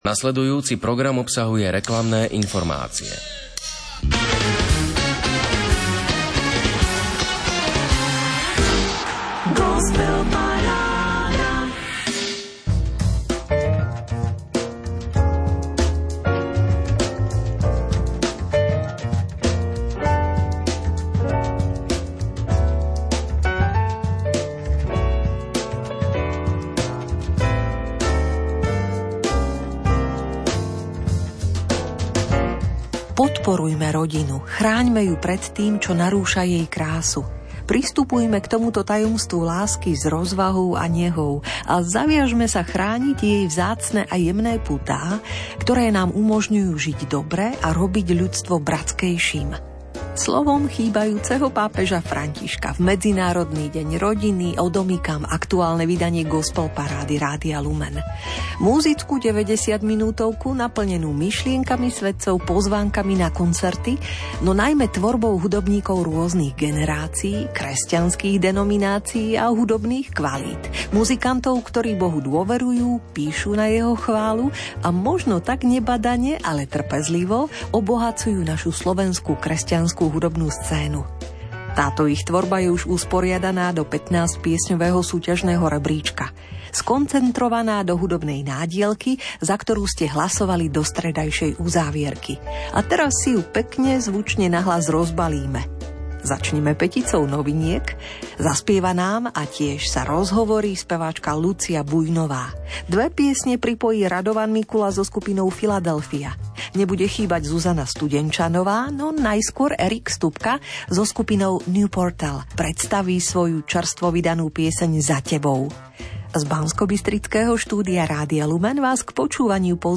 15.tohtoročné vydanie 15-piesňového súťažného rebríčka z tvorby aktérov SK kresťanskej hud. scény. ROZHOVOR